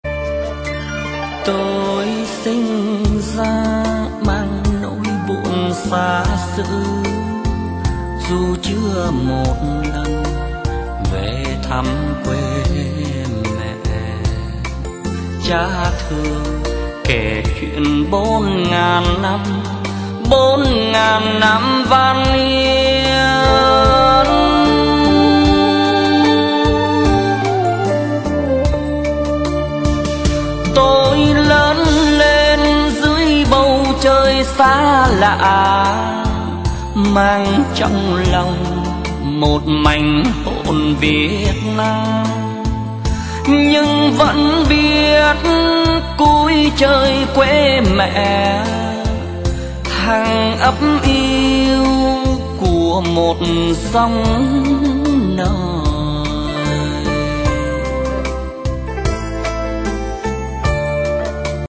Nhạc Chuông Nhạc Vàng - Nhạc Đỏ